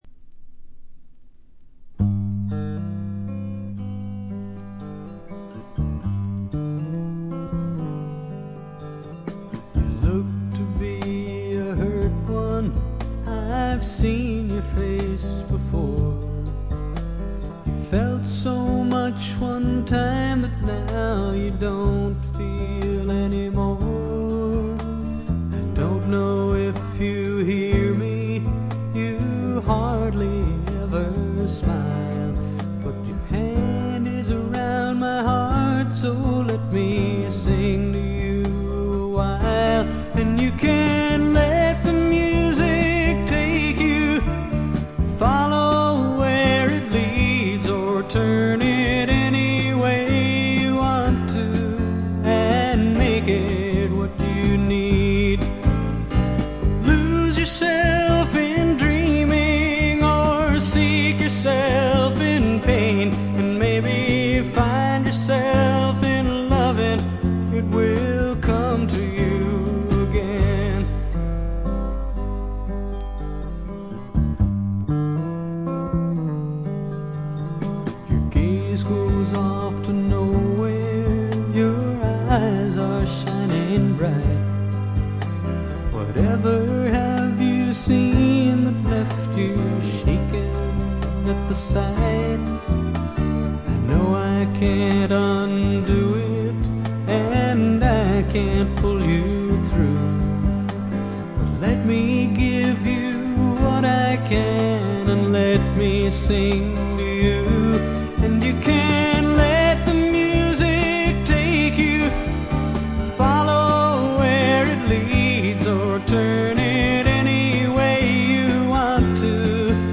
*** LP ： USA 1972
ほとんどノイズ感無し。
試聴曲は現品からの取り込み音源です。